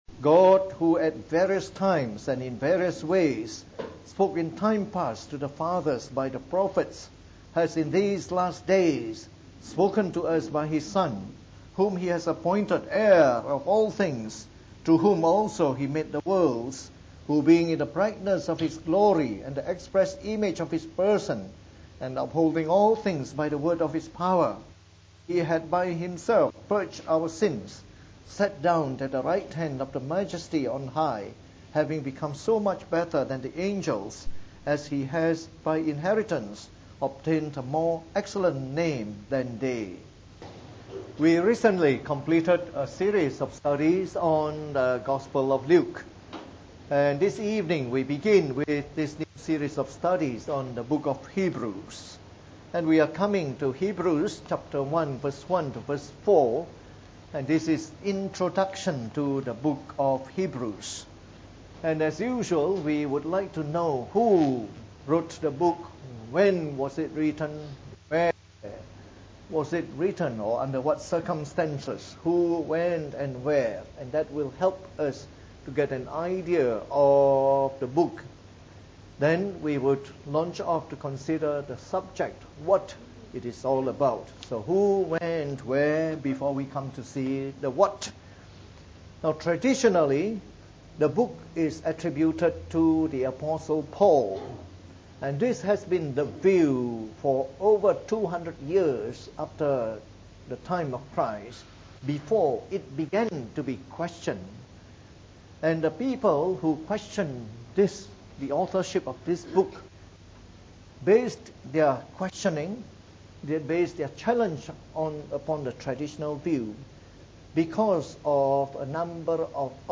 From our new series on the “Epistle to the Hebrews” delivered in the Evening Service.